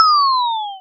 lose4.wav